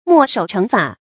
墨守成法 注音： ㄇㄛˋ ㄕㄡˇ ㄔㄥˊ ㄈㄚˇ 讀音讀法： 意思解釋： 見「墨守成規」。